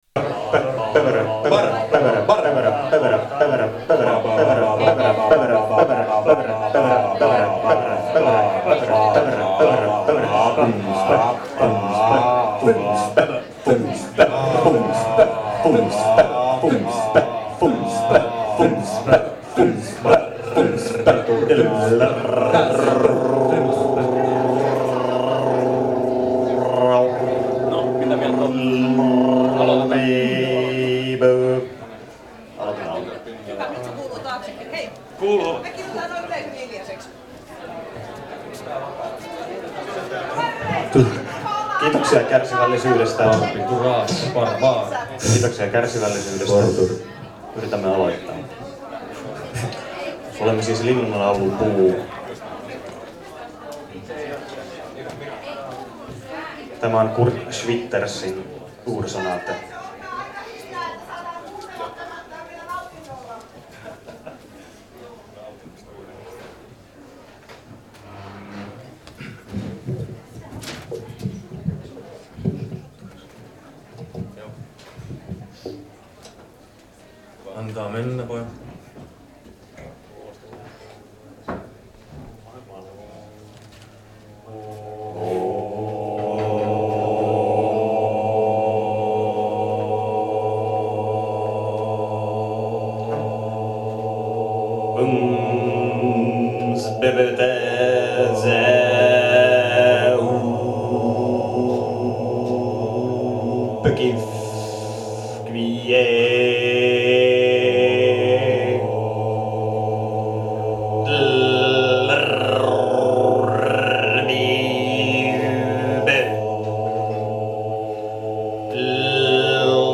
fabuleuse sonate de sons primitifs